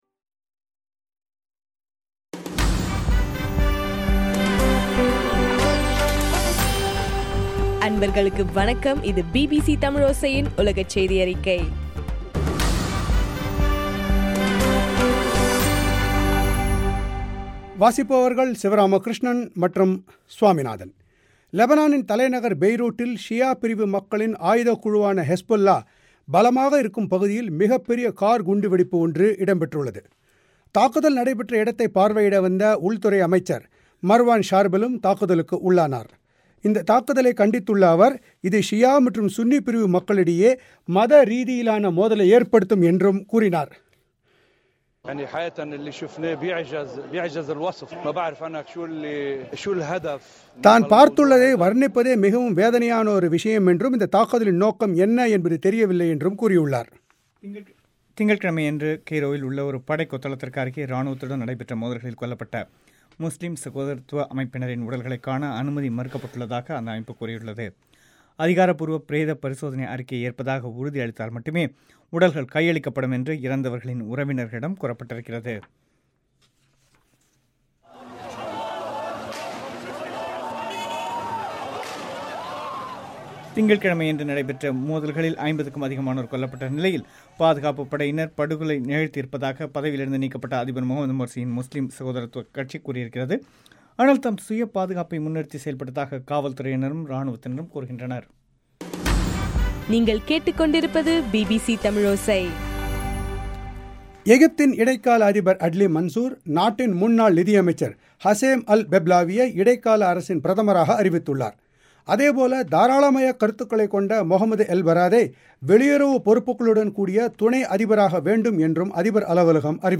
ஜூலை 9 பிபிசி தமிழோசையின் உலகச் செய்திகள்